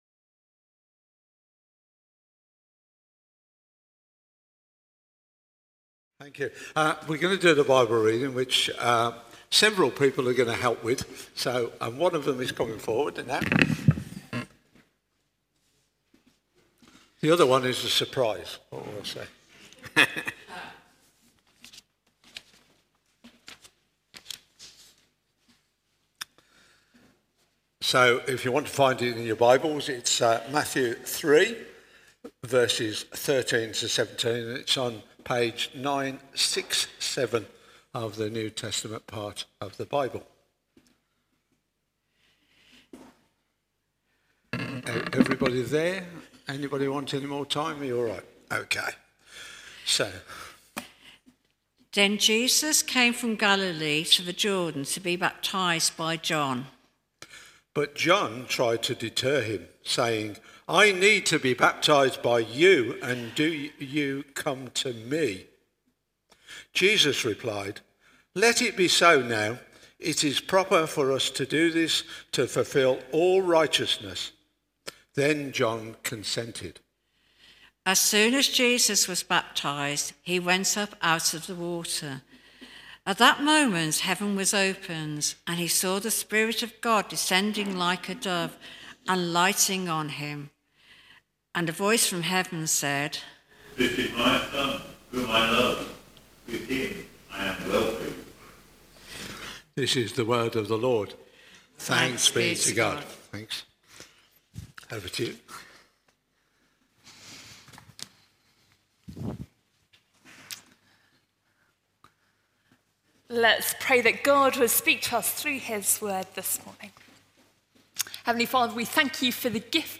Series: Stand-alone sermons